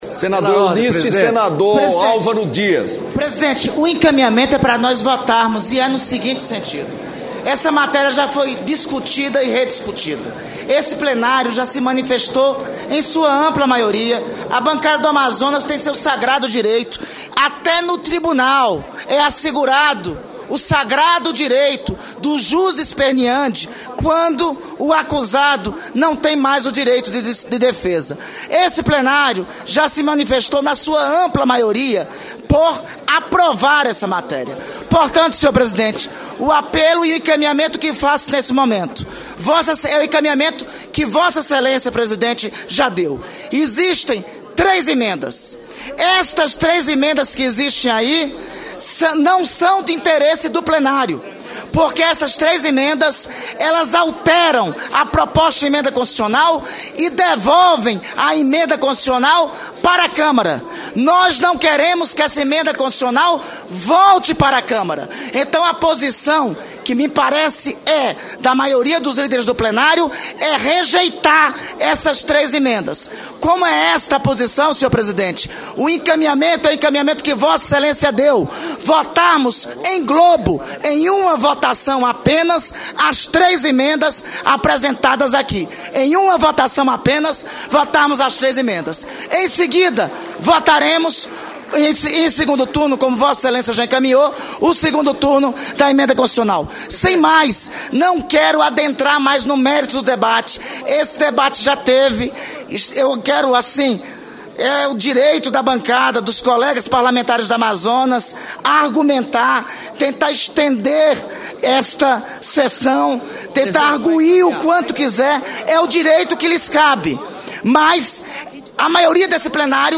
Sessão de aprovação da PEC da Música e do PL da profissão de vaqueiro (7)